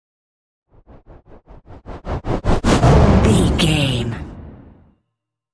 Dramatic whoosh to hit trailer
Sound Effects
Atonal
intense
tension
woosh to hit